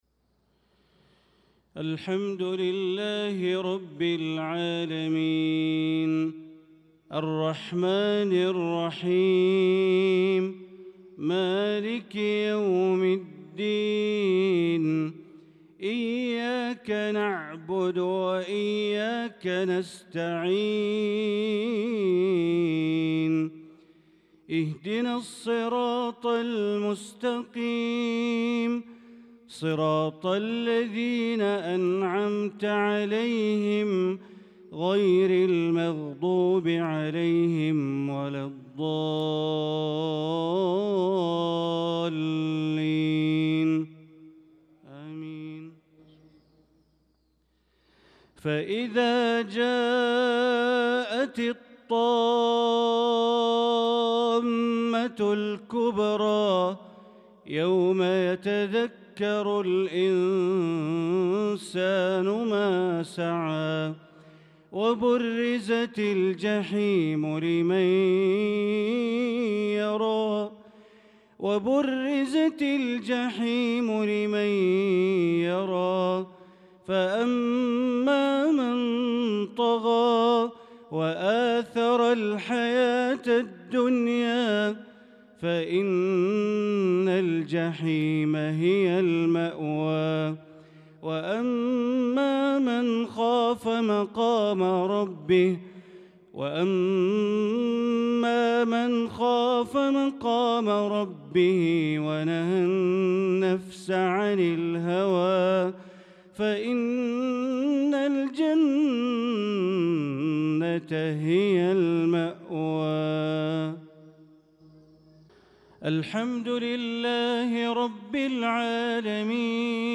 صلاة المغرب للقارئ بندر بليلة 14 شوال 1445 هـ
تِلَاوَات الْحَرَمَيْن .